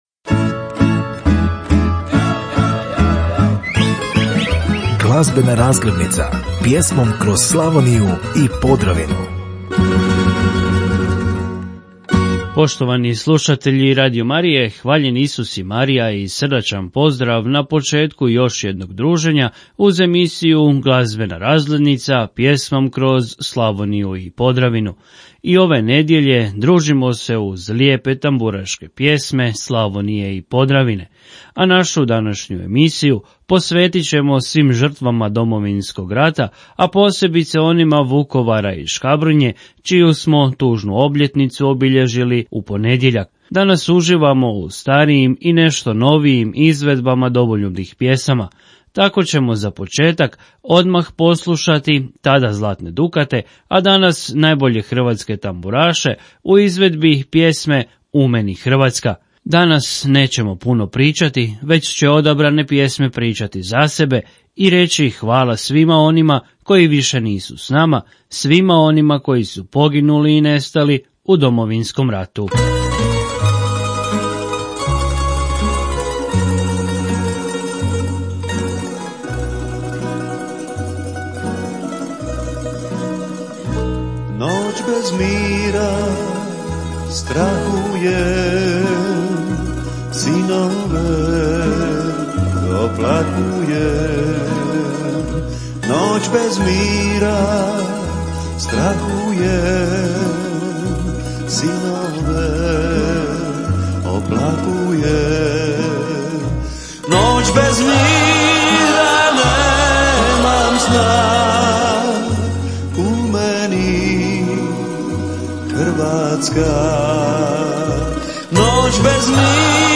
Glazbena razglednica - pjesme Slavonije i Podravine